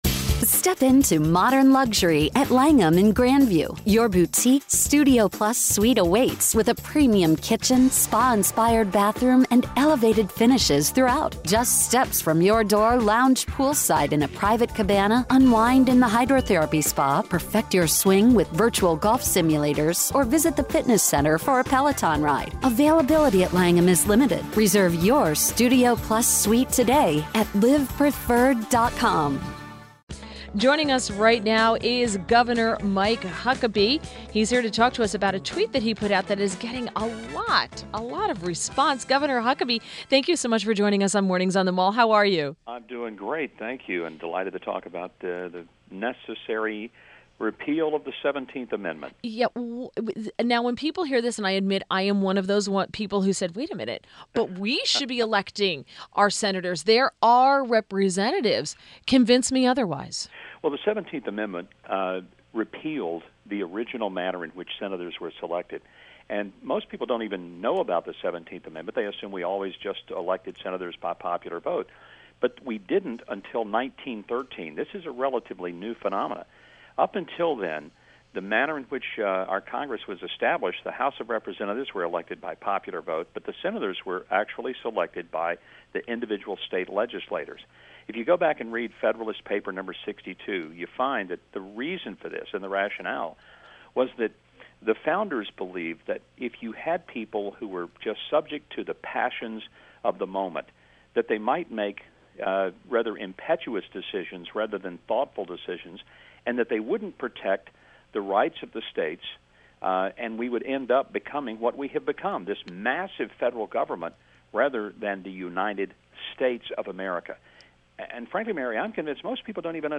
INTERVIEW - FORMER GOVERNOR MIKE HUCKABEE